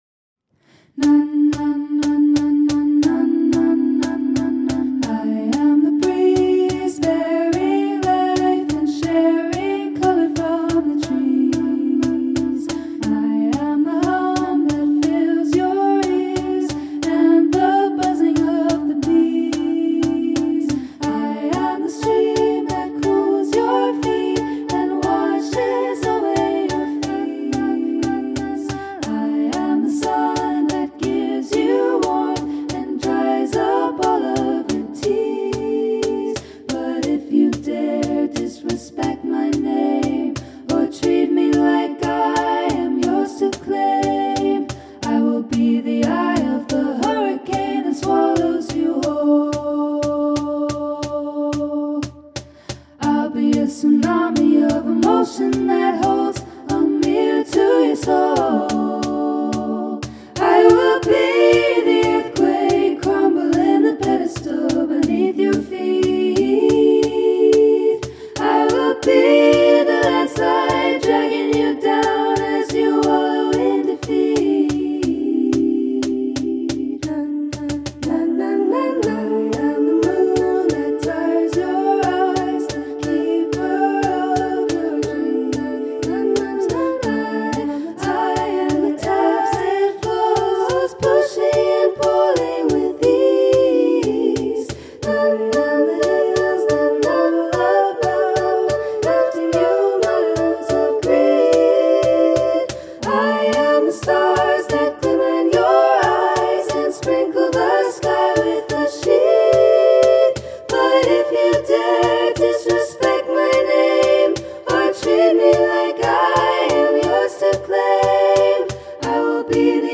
SSAA choir and cajón